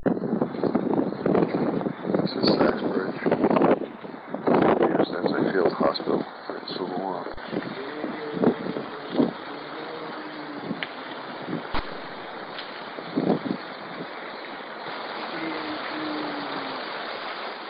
VIDEO  -  Infrared Photos  -  EVP
sachsbridge.wav